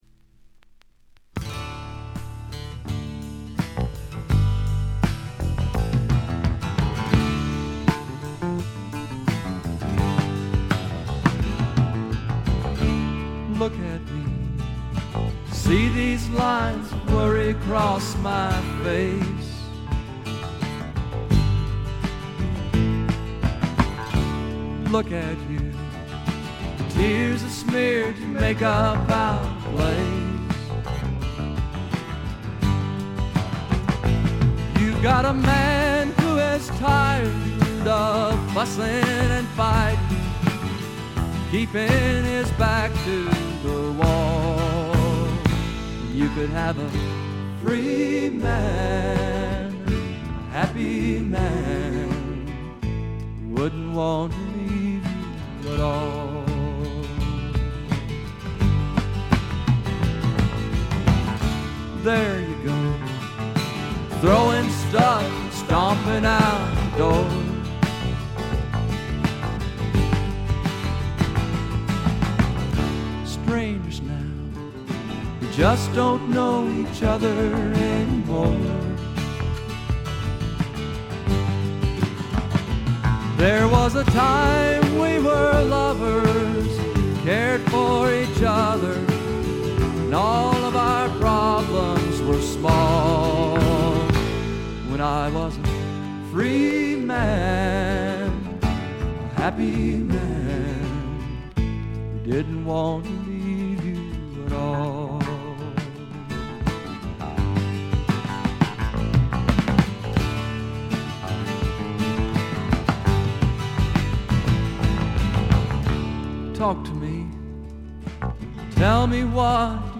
ところどころでバックグラウンドノイズ、チリプチ。
試聴曲は現品からの取り込み音源です。
Lead Vocals, Acoustic Guitar